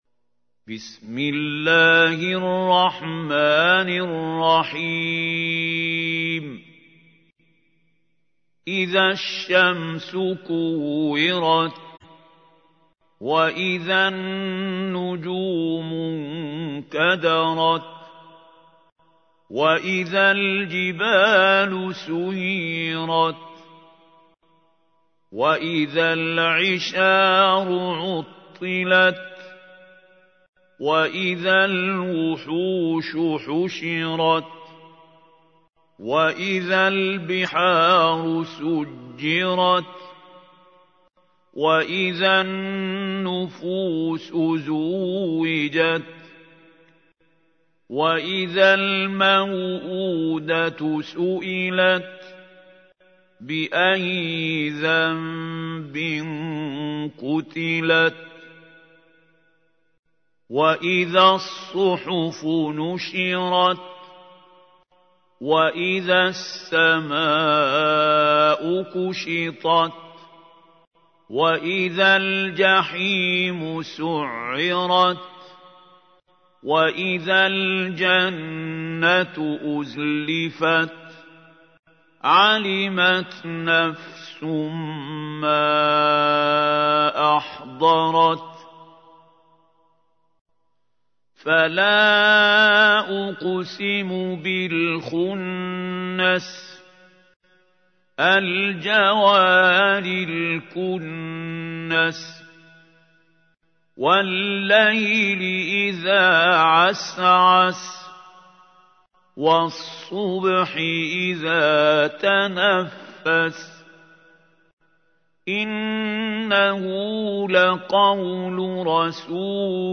تحميل : 81. سورة التكوير / القارئ محمود خليل الحصري / القرآن الكريم / موقع يا حسين